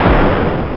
Amiga 8-bit Sampled Voice
Boom.mp3